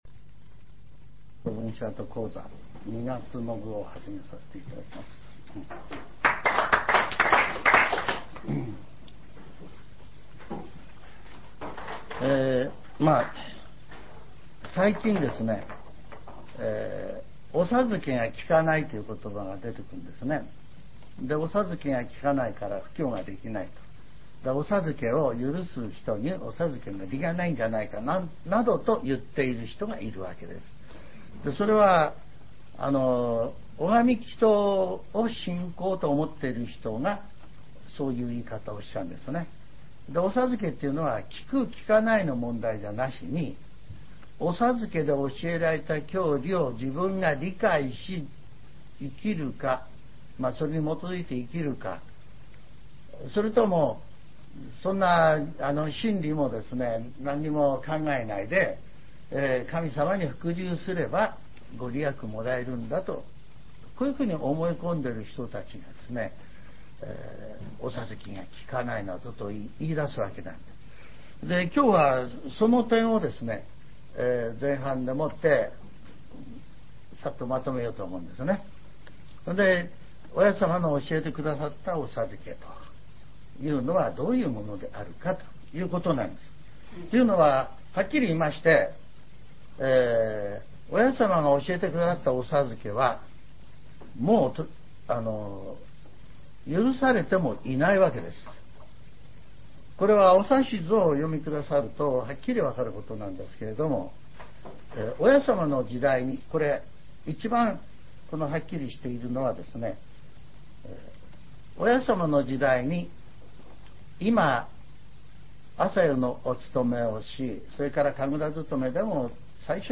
全70曲中6曲目 ジャンル: Speech